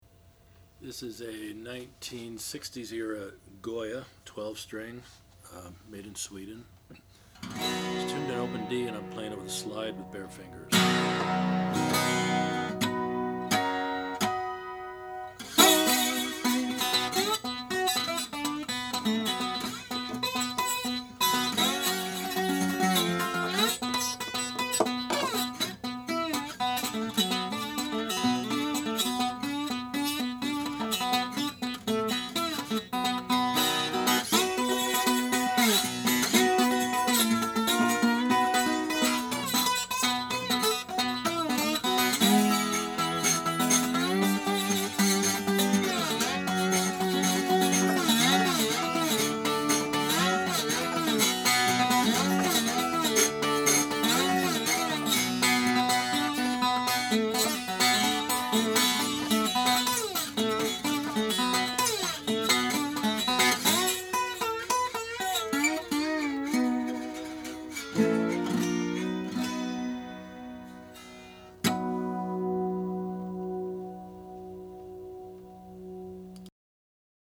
1966 Goya TS-5 12-String Natural
Overall, the guitar presents itself as a very nice package ... vintage vibe, quality materials and workmanship, playability .. and fantastic 12-string tones .. chimes on top and rumble down below.